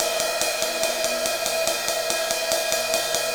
Ride Cymbal Pattern 43.wav